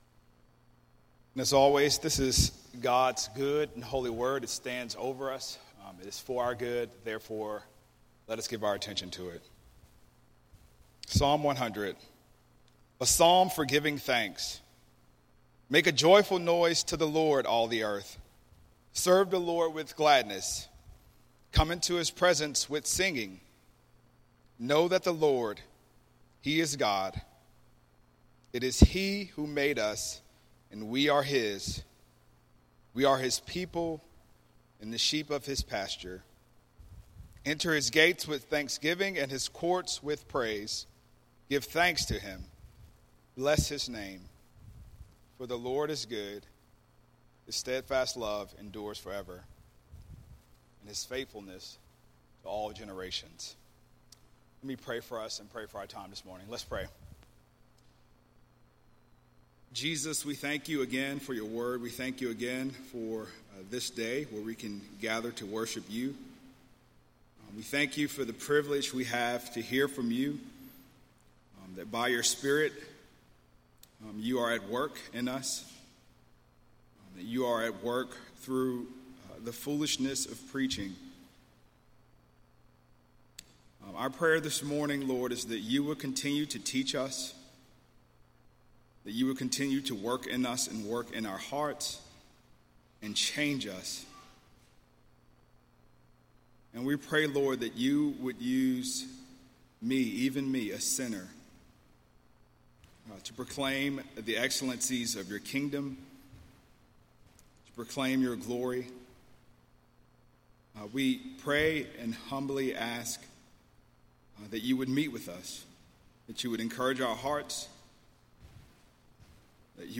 « Back to sermons page His Steadfast Love Endures Forever Sermon from June 14